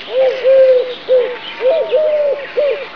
Pombo torcal (Pombo torcaz - Columba palumbus)
woodpigeon1.wav